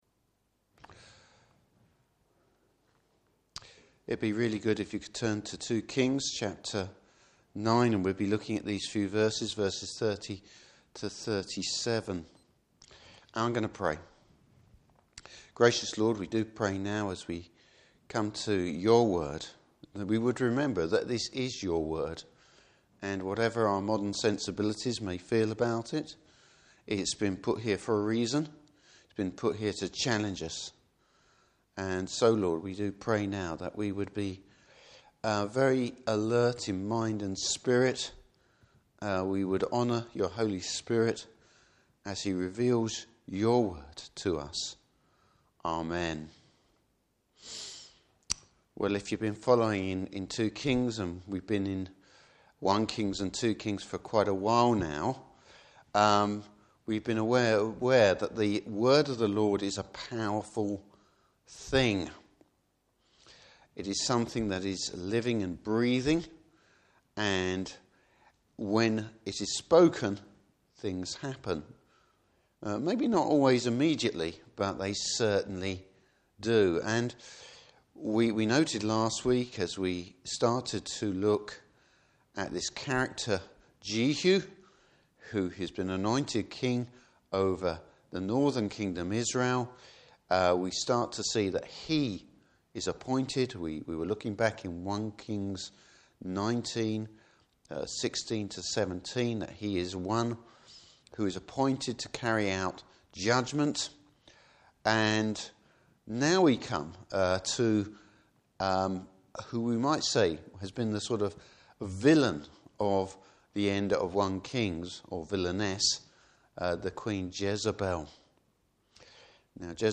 Service Type: Evening Service Bible Text: 2 Kings 9:30-37.